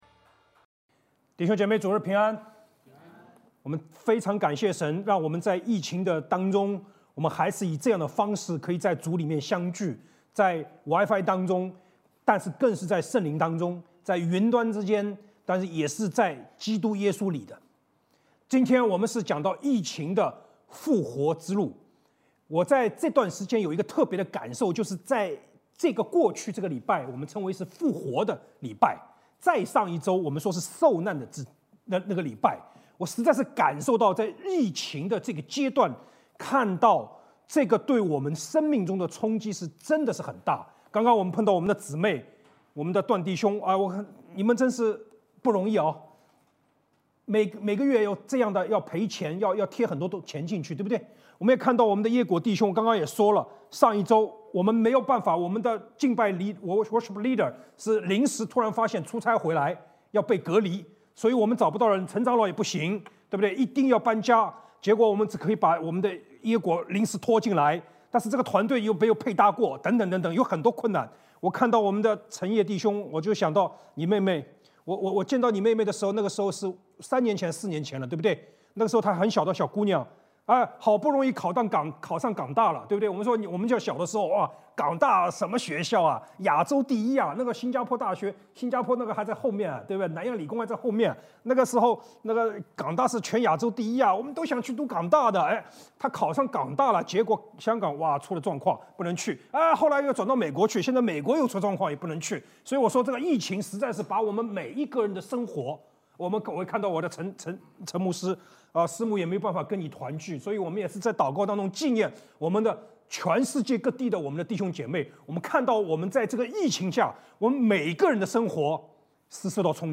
主日证道｜疫情下的复活之路